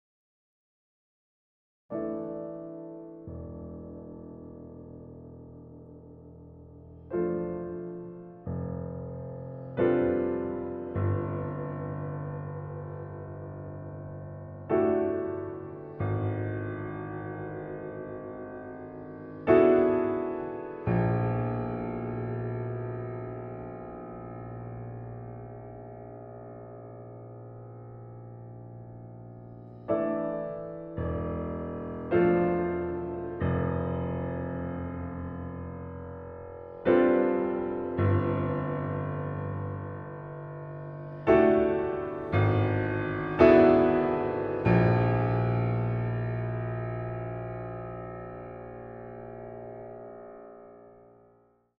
• Genres: Solo Piano, Classical